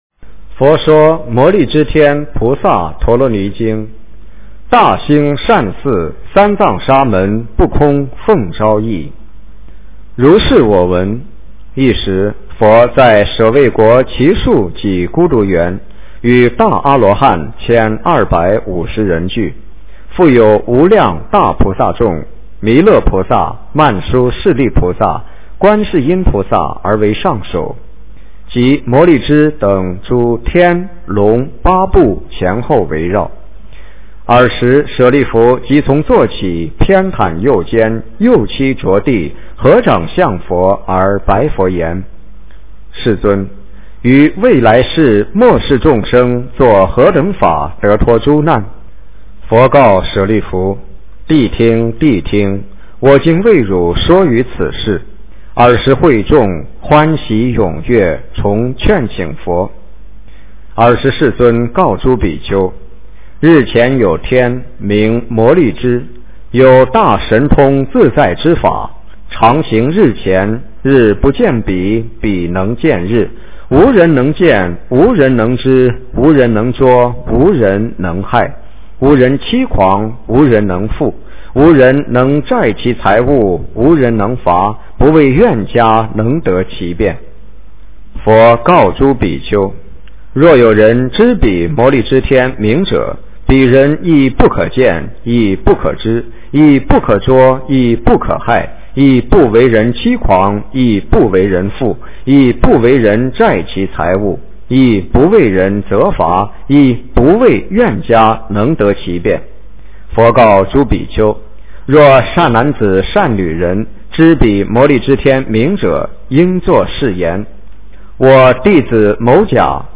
佛说摩利支天菩萨陀罗尼经 - 诵经 - 云佛论坛